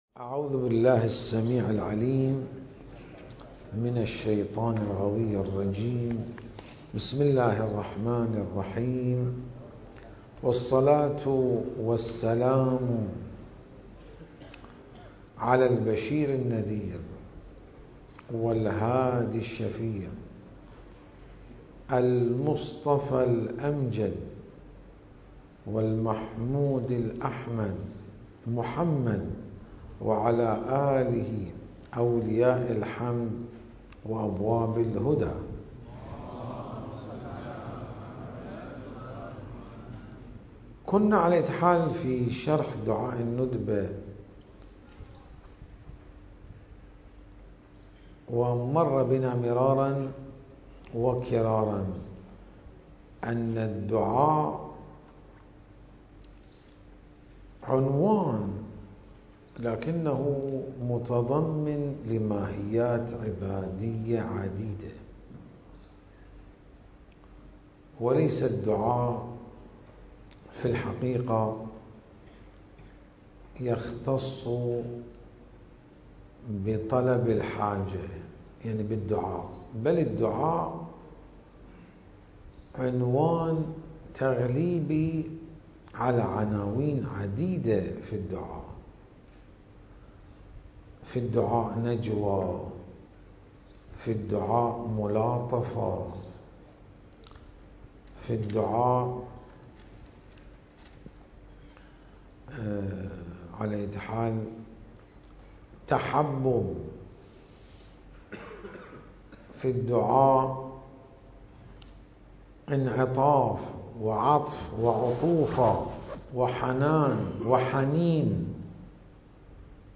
المكان: العتبة العلوية المقدسة